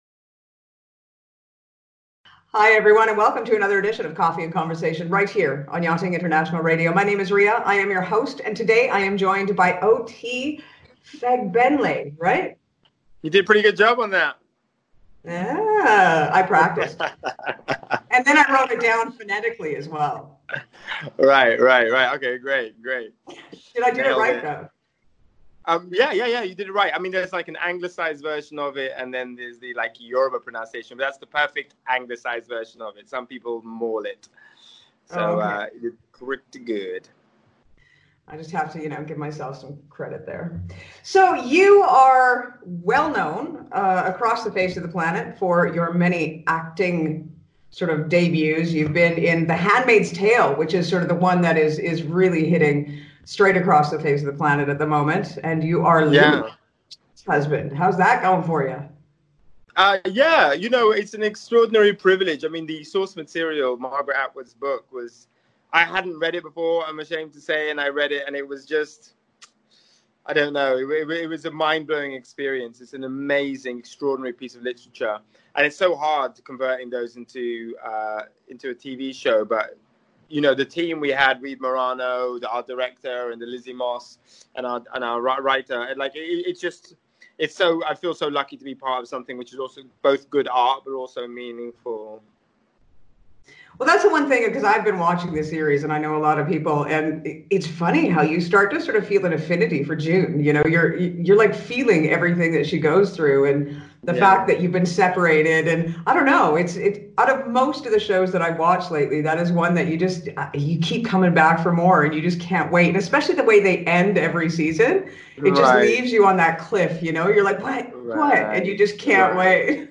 Coffee & Conversation with OT Fagbenle - Actor, Writer, and Director.